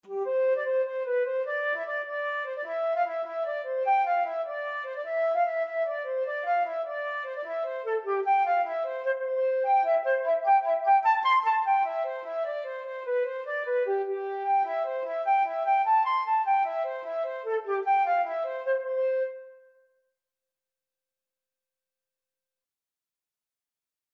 6/8 (View more 6/8 Music)
C major (Sounding Pitch) (View more C major Music for Flute )
Flute  (View more Easy Flute Music)
Traditional (View more Traditional Flute Music)